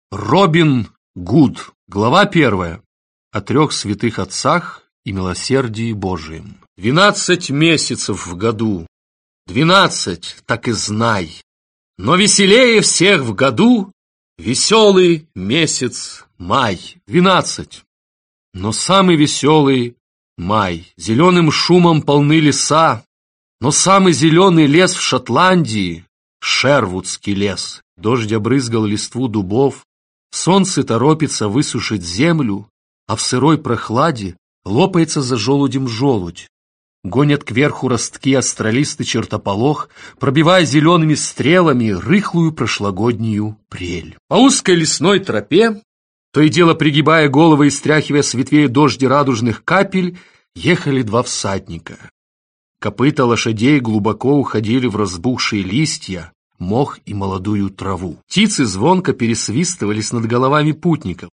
Аудиокнига Робин Гуд | Библиотека аудиокниг